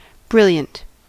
Ääntäminen
US : IPA : [ˈbrɪl.jənt]